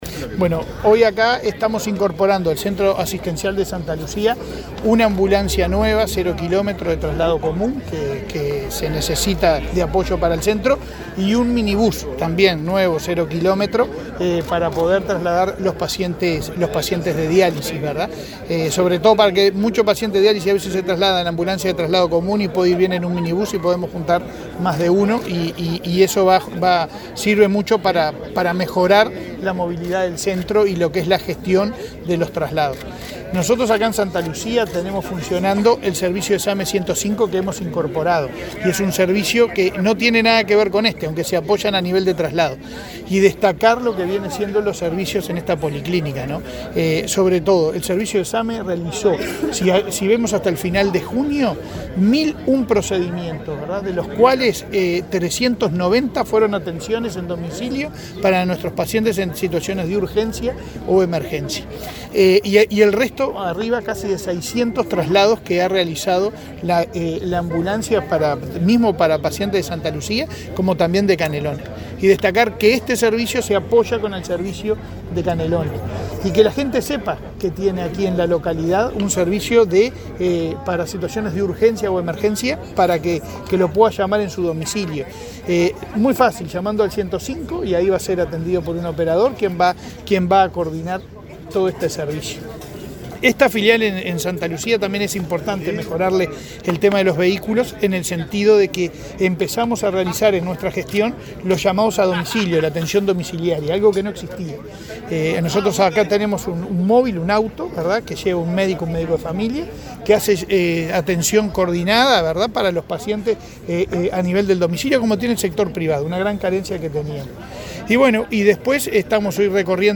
El presidente de ASSE, Leonardo Cipriani, dialogó con la prensa durante el acto de entrega de una ambulancia nueva y un minibus para el centro
Declaraciones del presidente de ASSE, Leonardo Cipriani 21/08/2023 Compartir Facebook X Copiar enlace WhatsApp LinkedIn El presidente de ASSE, Leonardo Cipriani, dialogó con la prensa durante el acto de entrega de una ambulancia nueva y un minibus para el centro asistencial de la localidad de Santa Lucía, en el departamento de Canelones.